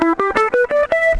Le guitariste électrique - La pentatonique mineure
Gamme Pentatonique Mineure de Mi (cliquez pour écouter) Schémas des positions
Une gamme pentatonique mineure se compose de 5 notes.